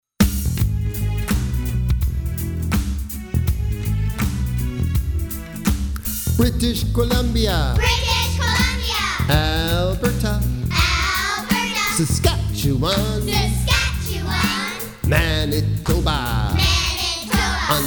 Children's Song-Chant about the Canadian Provinces